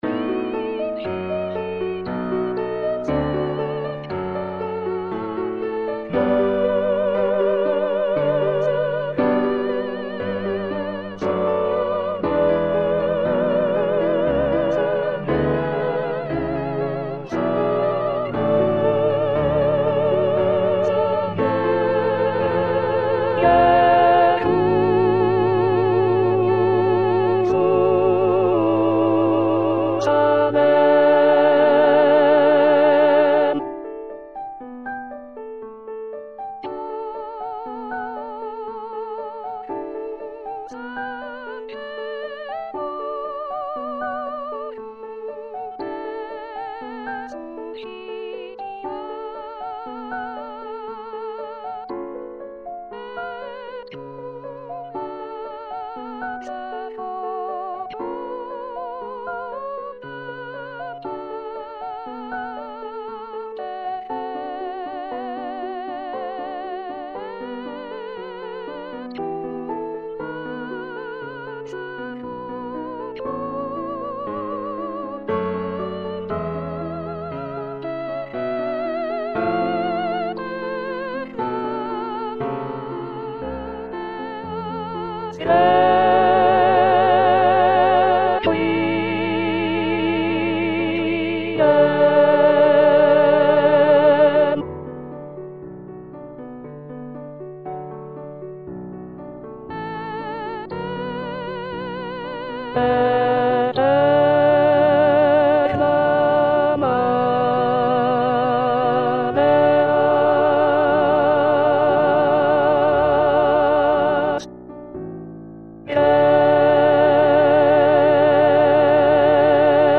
ATTENTION : ces fichiers audio comportent peu ou pas de nuances, il ne s'agit (normalement!) que des bonnes notes à la bonne place
avec la bonne durée chantées par des voix synthétiques plus ou moins agréables .